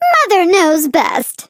flea_lead_vo_02.ogg